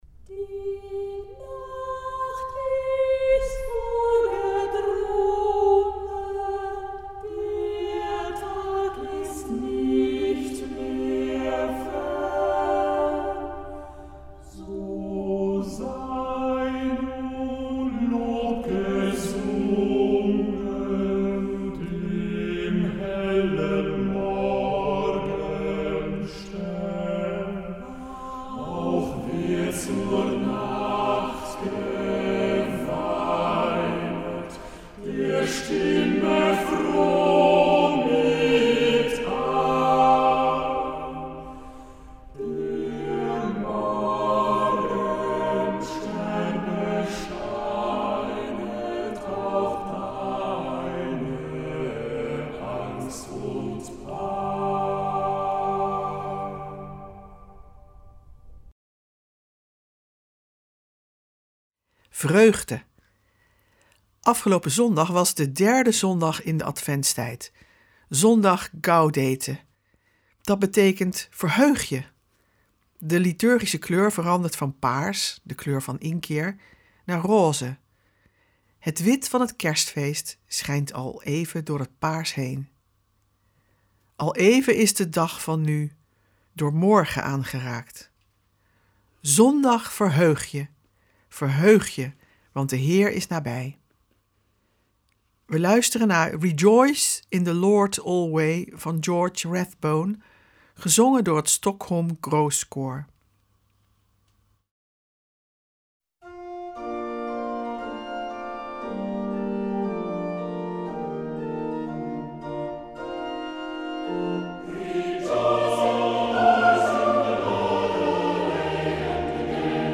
Ook dit jaar komen klassieke Bijbelteksten aan de orde, teksten die al eeuwen in de kerk juist in deze weken van Advent zijn gelezen. We verbinden ze met onze tijd en we luisteren naar prachtige muziek en poëzie.
Luister vandaag naar vreugdevolle muziek: Rejoice!